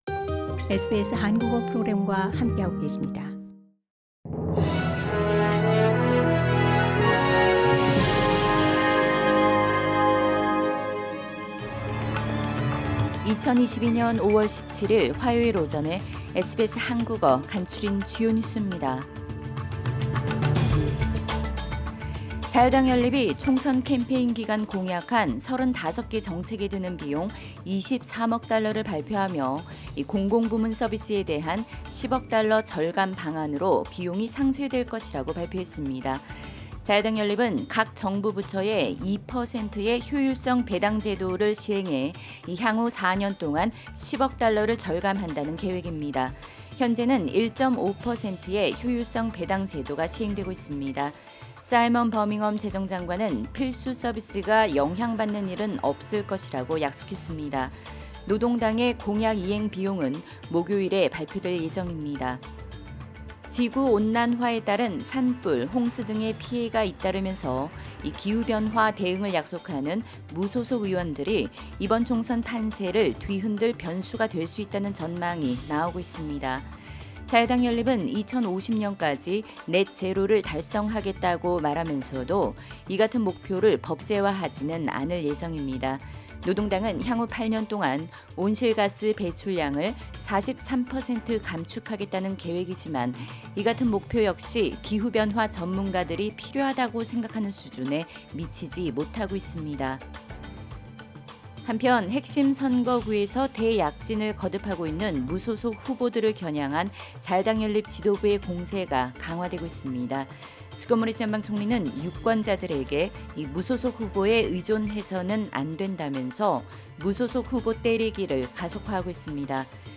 SBS 한국어 아침 뉴스: 2022년 5월 17일 화요일